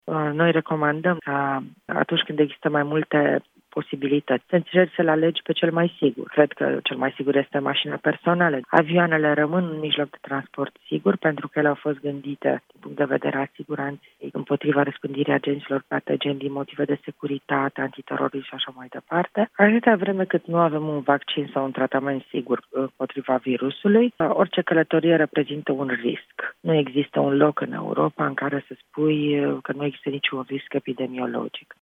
Într-un interviu acordat Europa FM, comisarul european Adina Vălean spune că, în această perioadă, cel mai sigur mijloc de transport rămâne mașina personală.